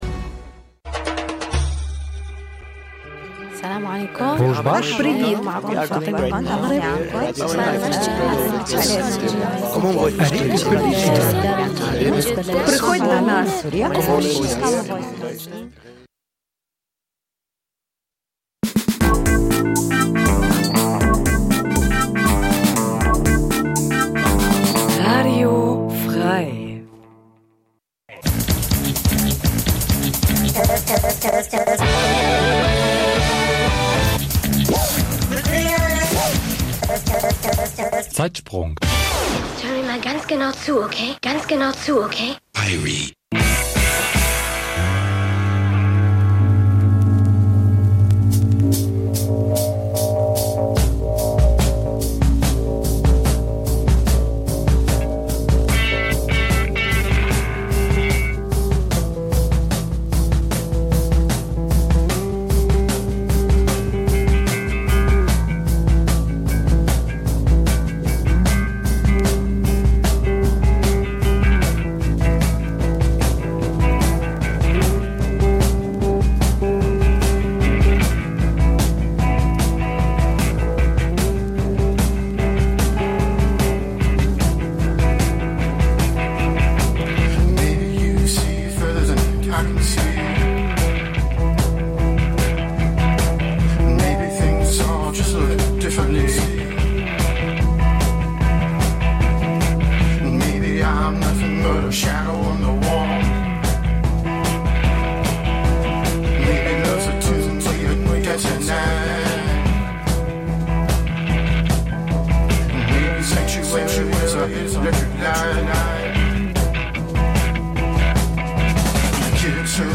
Schr�ge Originale stehen noch schr�geren Coverversionen gegen�ber.
So entdecken wir f�r unsere H�rer musikalische Grausamkeiten genauso, wie "Unerh�rtes" von einst und heute. Wir w�nschen gute Schallplattenunterhaltung und guten Empfang.
Musik vergangener Tage Dein Browser kann kein HTML5-Audio.